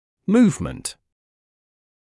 [‘muːvmənt][‘муːвмэнт]движение, перемещение